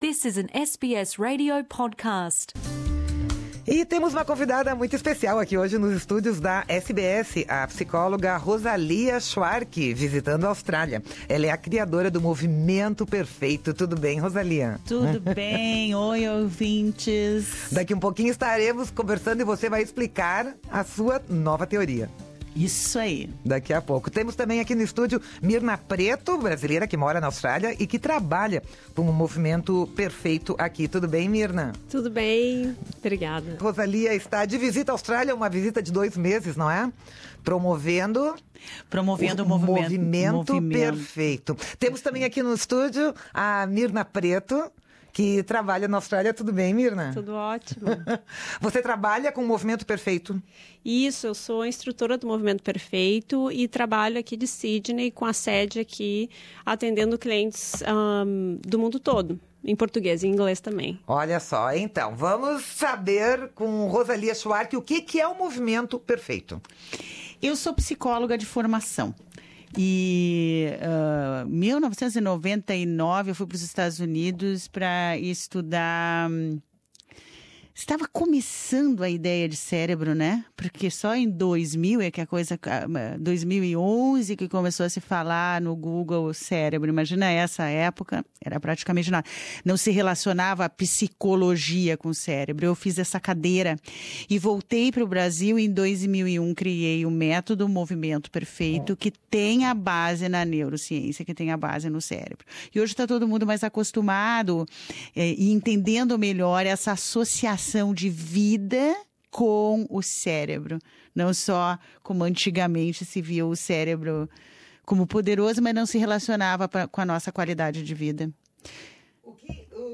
Neste podcast da entrevista que deu ao ao Programa Português da Rádio SBS